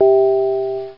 Chime Low Sound Effect
Download a high-quality chime low sound effect.
chime-low.mp3